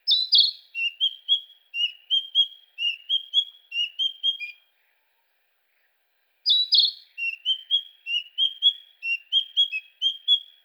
ヘルプ 詳細情報 キビタキ 大きさ スズメくらい 季節 春秋 色 黒・黄 特徴 全長13.5cm。姿も声も美しくバードウォッチャー憧れの鳥のひとつ。